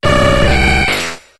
Cri d'Artikodin dans Pokémon HOME.